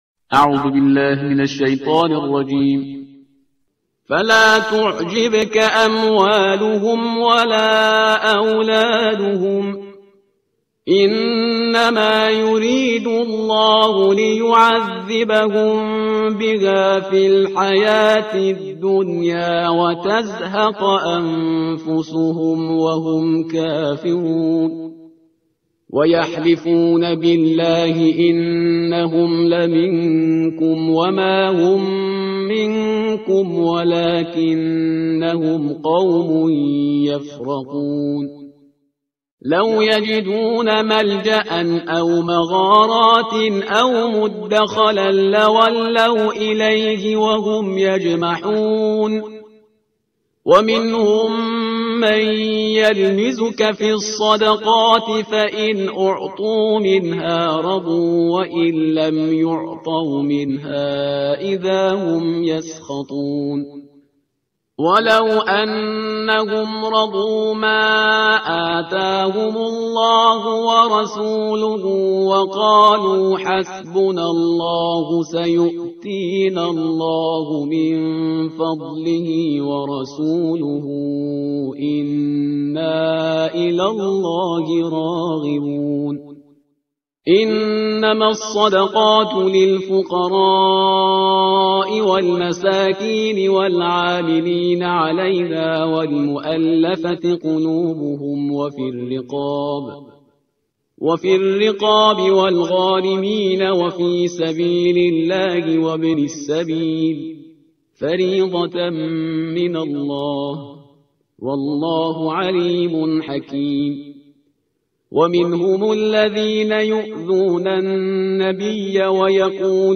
ترتیل صفحه 196 قرآن با صدای شهریار پرهیزگار